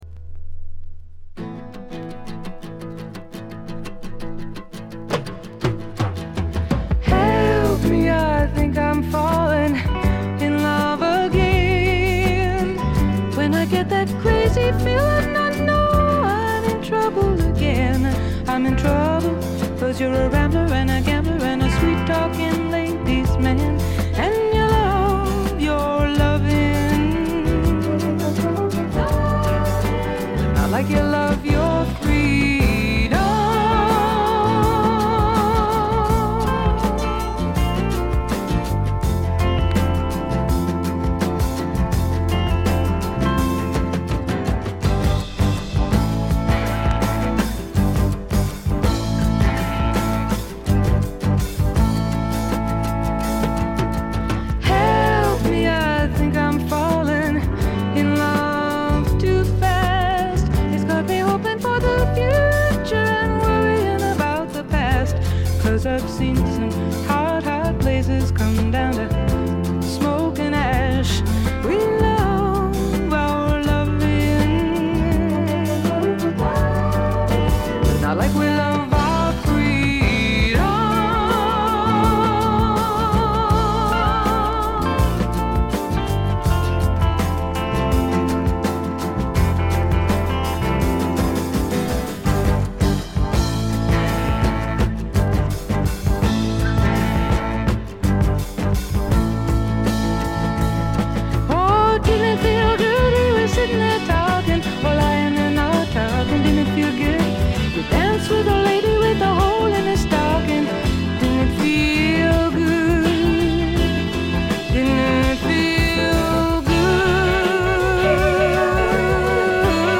A2序盤で軽いプツ音1回。
試聴曲は現品からの取り込み音源です。